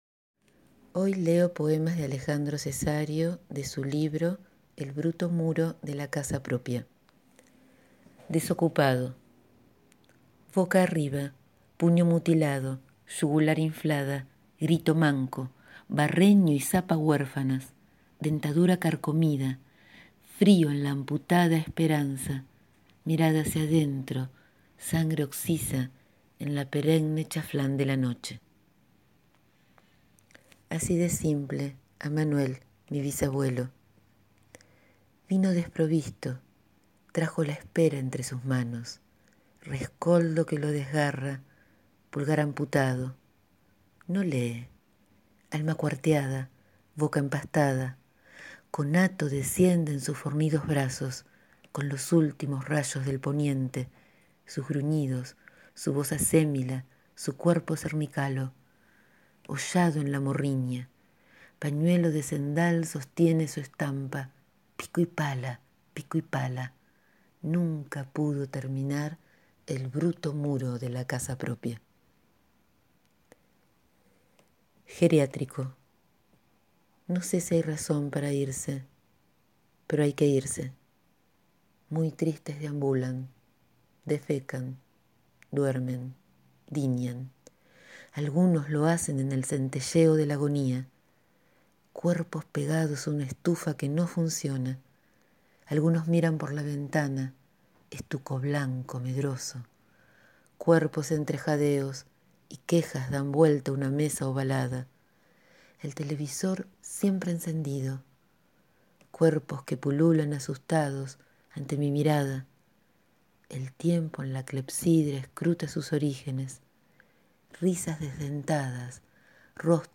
Hoy leo poemas de Alejandro Cesario* extraído de su libro «El bruto muro de la casa propia».